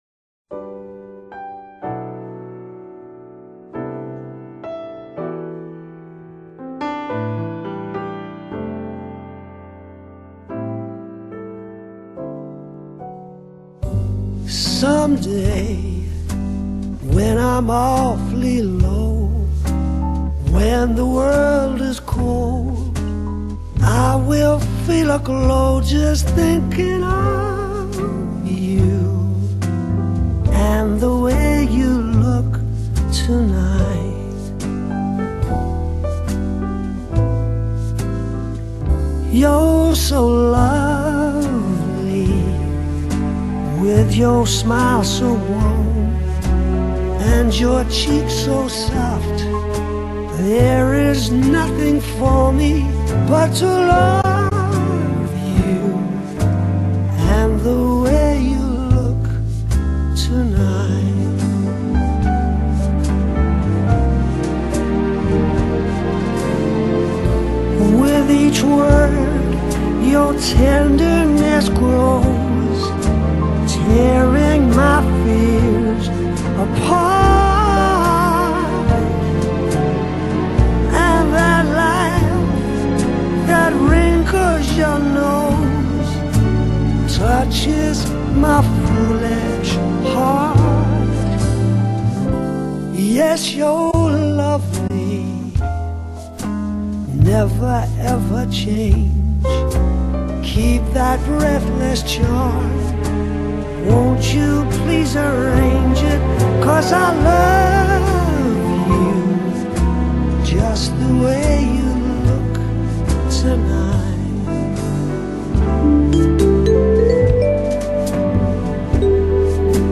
爵士美國情歌
以風格統一的性感白人爵士，演唱世間男女傳唱的愛戀情歌經典。